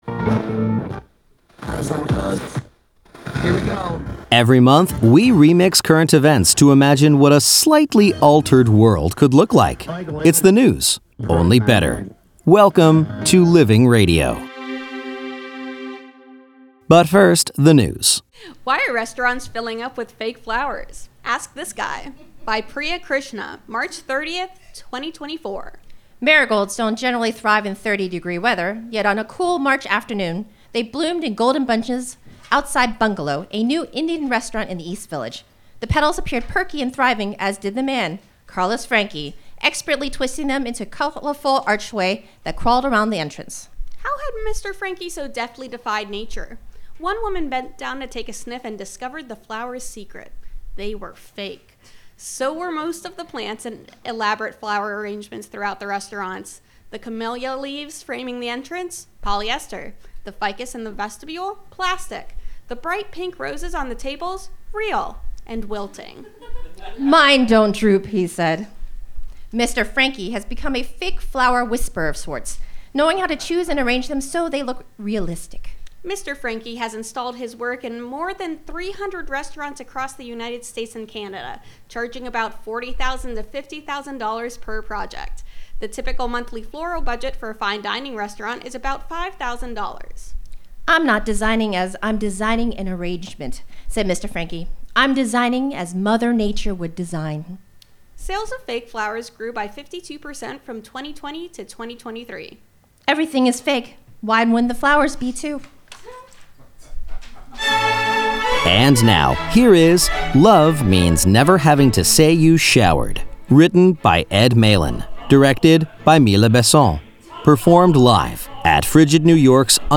performed live for Living Radio at FRIGID New York’s UNDER St. Mark’s Theater, April 1, 2024